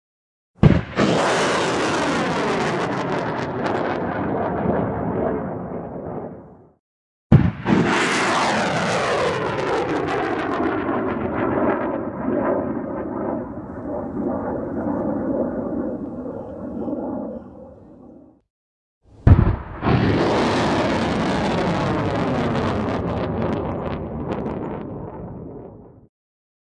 Download Warfare sound effect for free.
Warfare